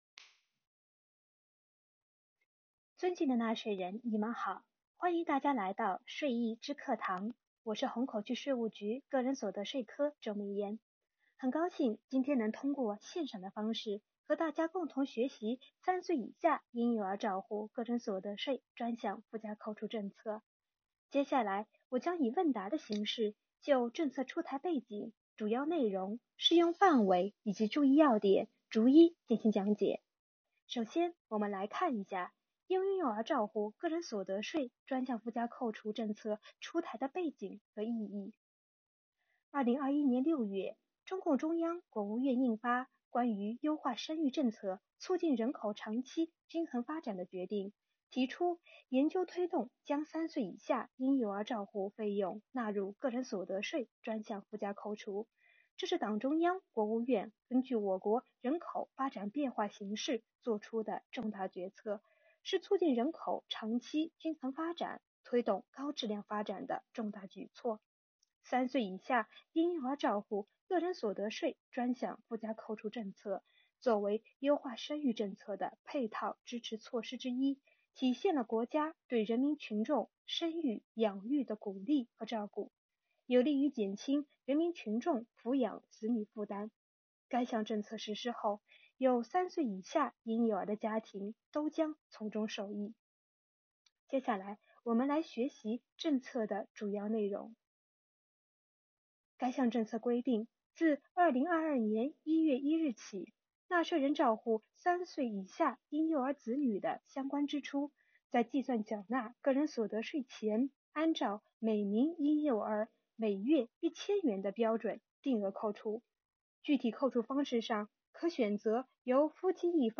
为大家详细讲解3岁以下婴幼儿照护个人所得税专项附加扣除政策